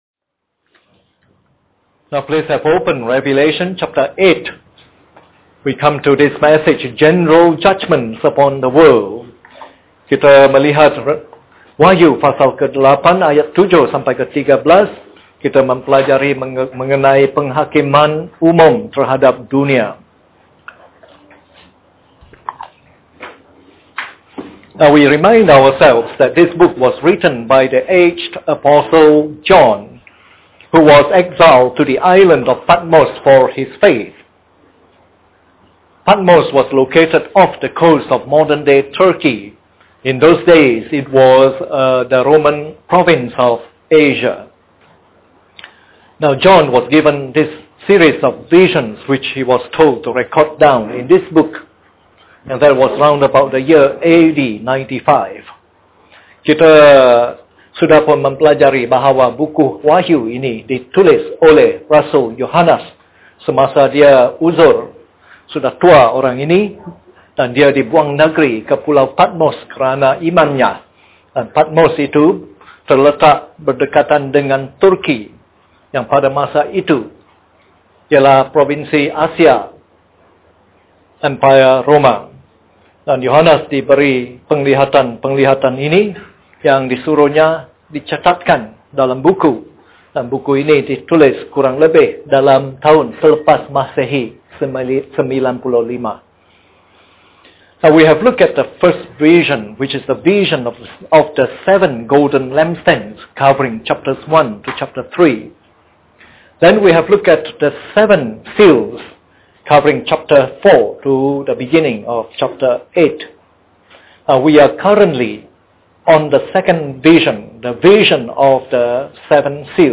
This is part of the morning service series on “Revelation”.